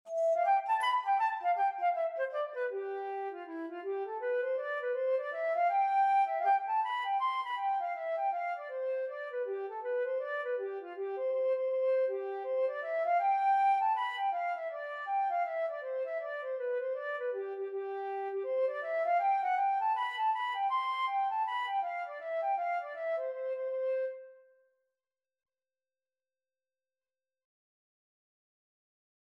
C major (Sounding Pitch) (View more C major Music for Flute )
4/4 (View more 4/4 Music)
Flute  (View more Easy Flute Music)
Traditional (View more Traditional Flute Music)